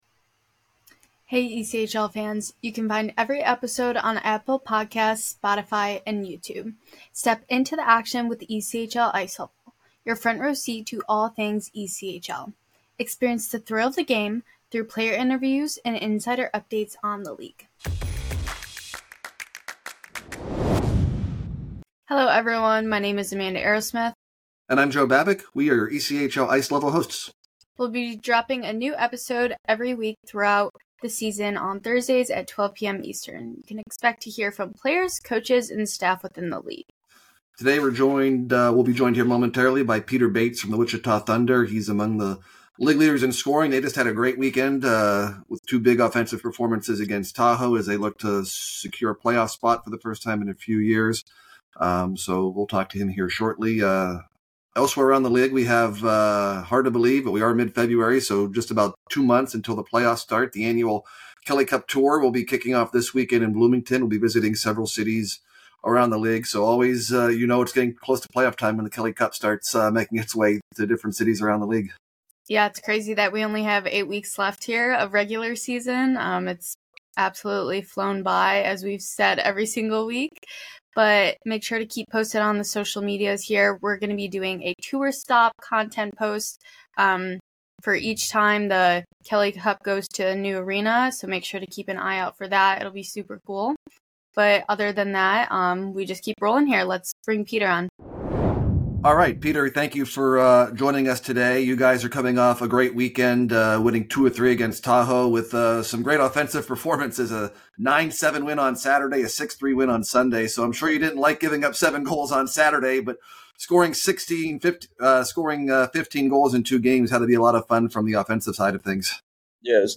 Experience the thrill of the game through player interviews, and insider updates on the league.